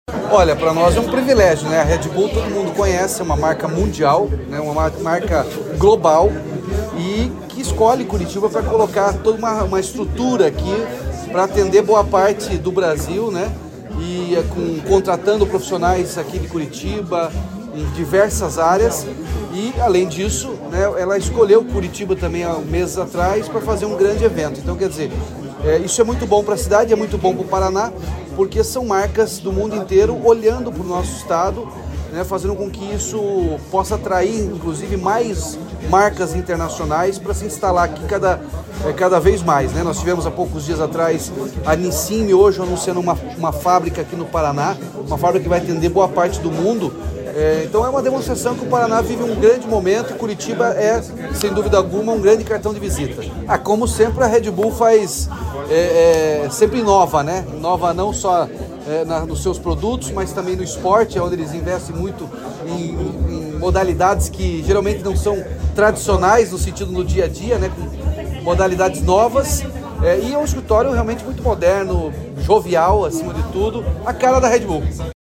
Sonora do governador Ratinho Junior sobre a inauguração do escritório da Red Bull em Curitiba